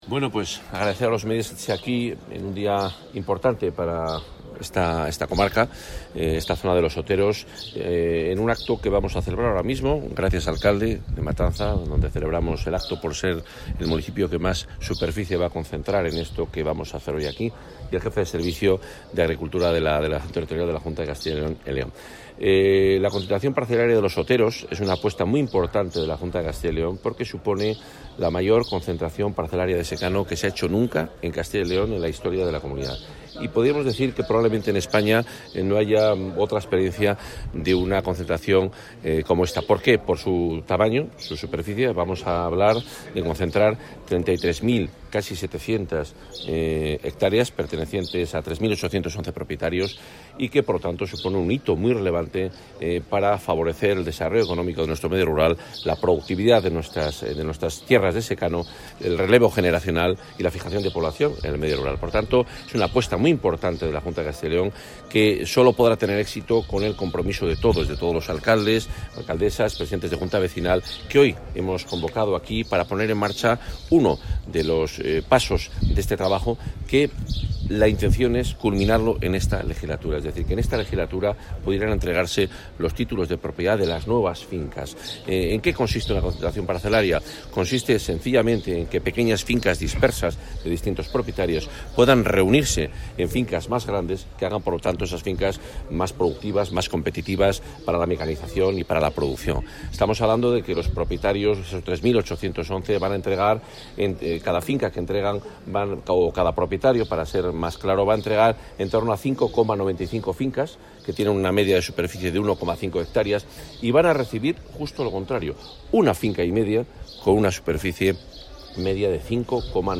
Declaraciones del consejero de Fomento y Medio Ambiente
Declaraciones y atención a medios.
Se adjuntan declaraciones del consejero de Fomento y Medio Ambiente, Juan Carlos Suárez-Quiñones, con motivo de la constitución de la Comisión Local de la zona de concentración parcelaria de Los Oteros (León-Valladolid), hoy en Matanza de los Oteros.